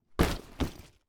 household
Duffle Bag Drop Cement Floor 3